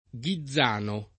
vai all'elenco alfabetico delle voci ingrandisci il carattere 100% rimpicciolisci il carattere stampa invia tramite posta elettronica codividi su Facebook Ghizzano [ g i zz# no ] top. (Tosc.) — in val d’Era — sim. il cogn.